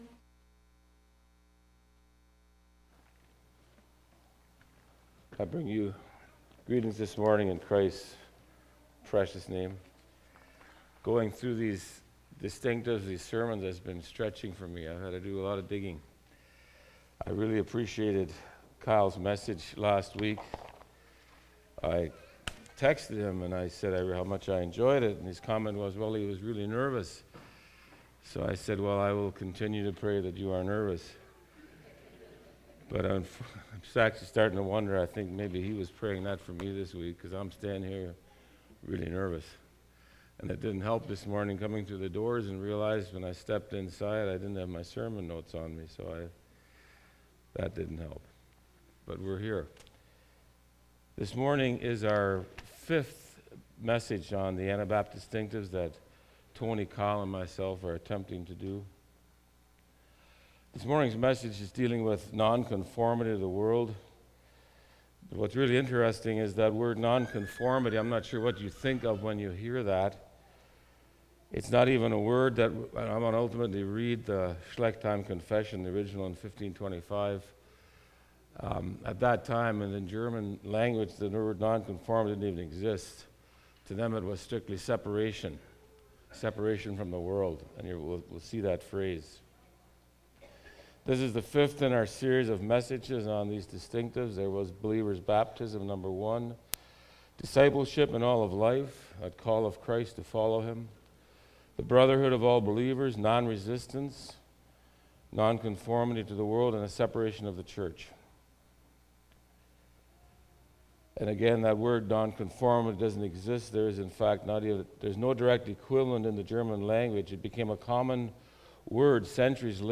Sermons/Media - Faith Mennonite Church